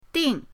ding4.mp3